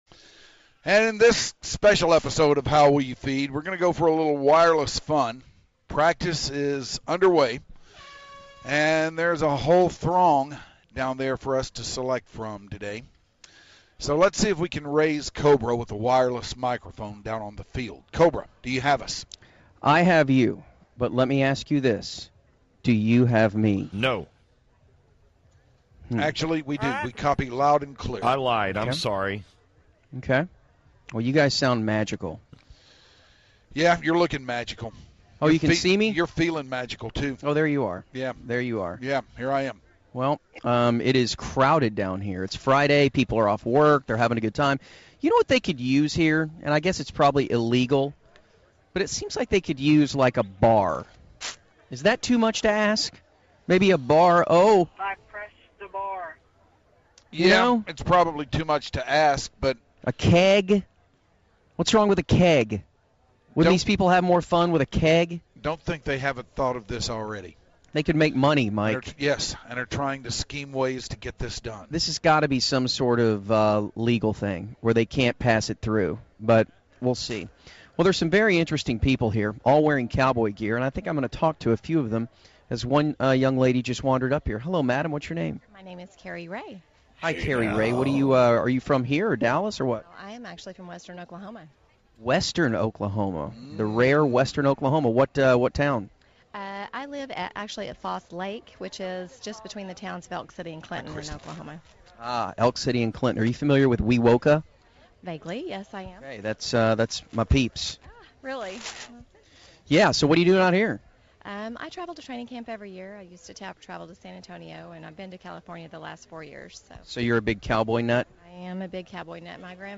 More wireless fun from training camp.